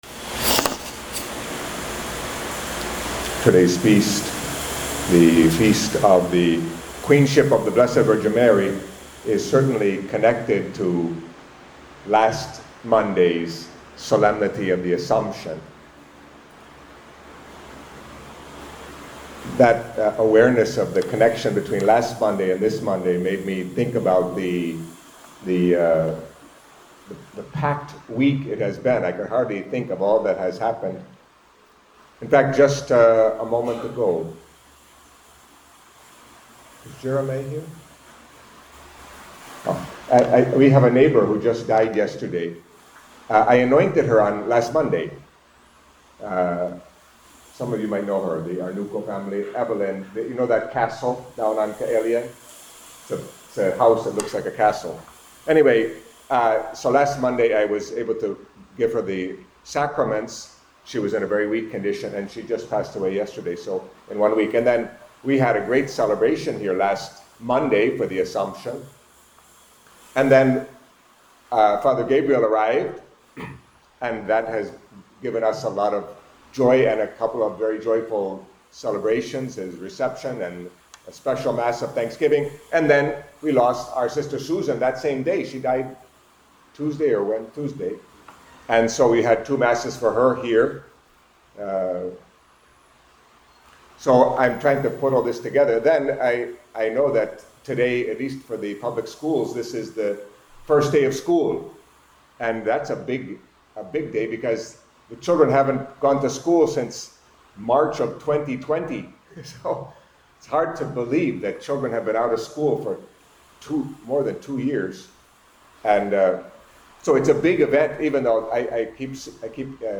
Catholic Mass homily for The Queenship of Mary